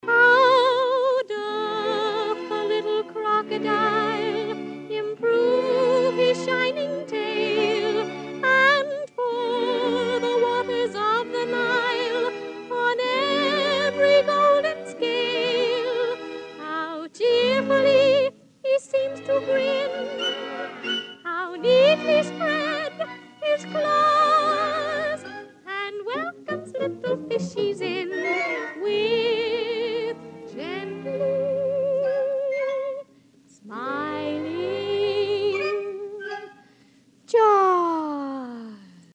5. Alice sings: ‘How doth the little crocodile…’